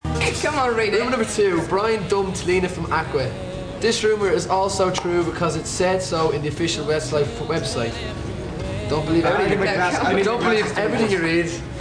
Parts of the interview (the blue ones) are also recorded in MP3 format!!!